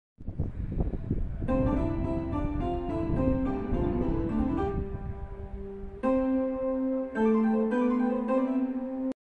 SAMSUNG STARTUP AND SHUTDOWN! Credits to original owner.